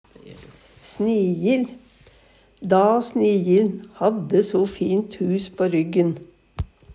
snijil - Numedalsmål (en-US)